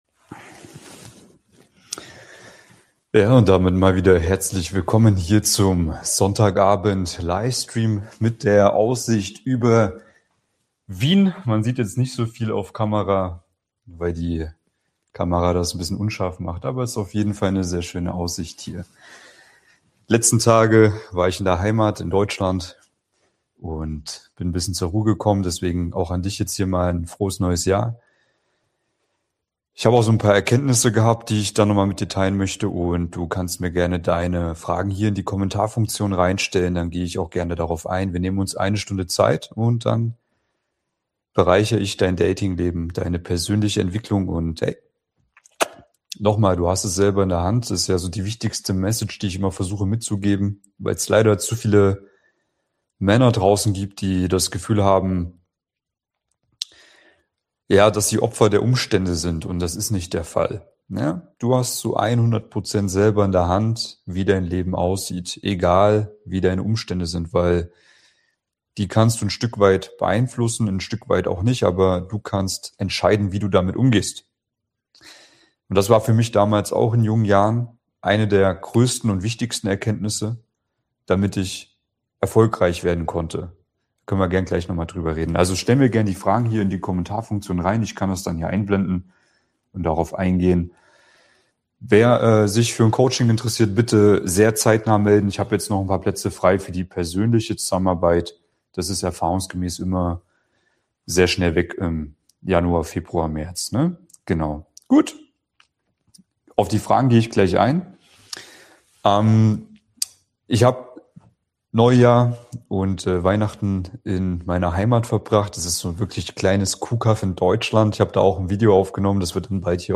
2026 endlich zur passenden Partnerin (als zu verkopfter Mann) - Live Q&A ~ Mission Traumfrau – Für Männer mit Anspruch Podcast